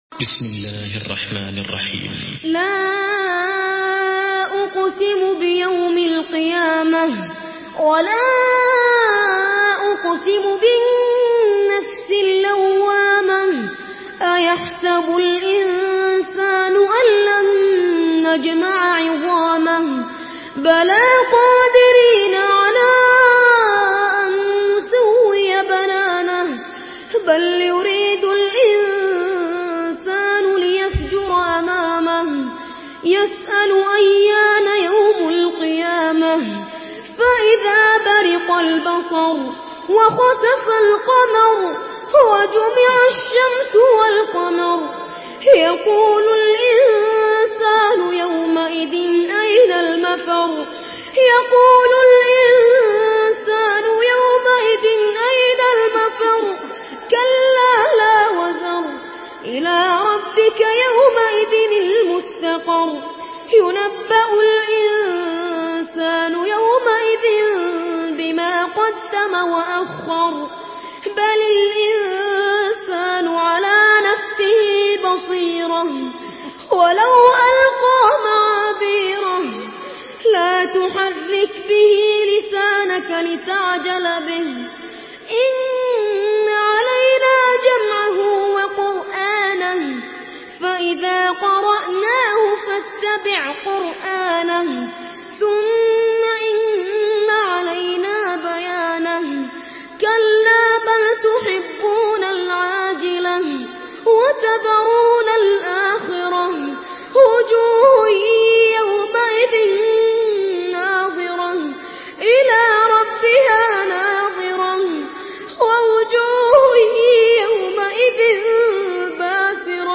Murattal Hafs An Asim